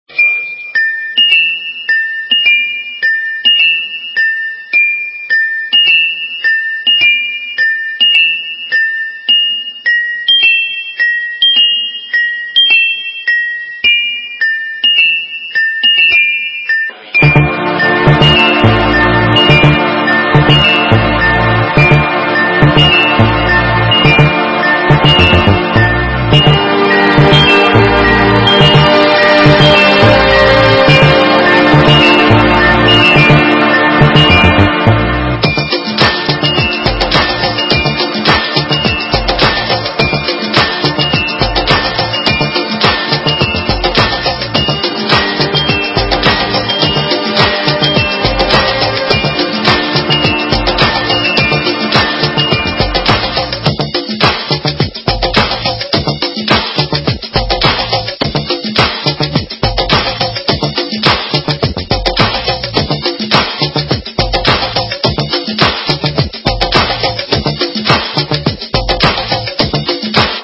Громкая милодия, типа реклама телефона каковата... :)